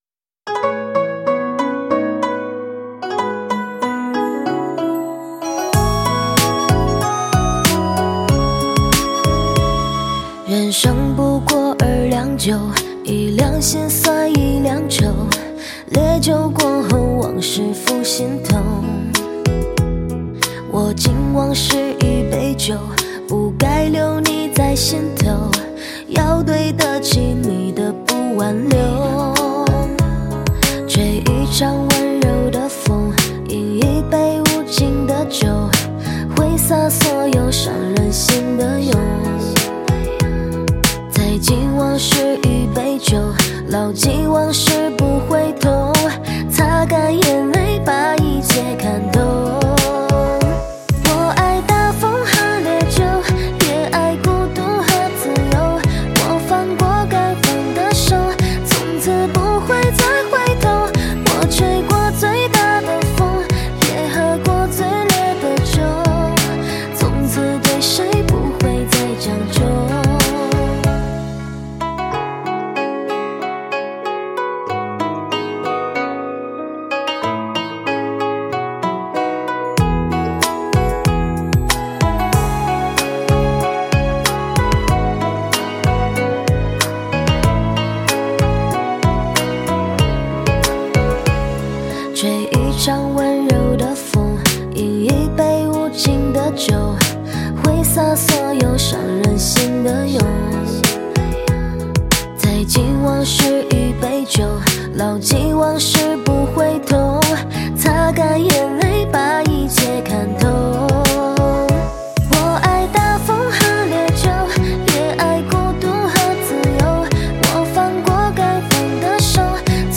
伤感歌曲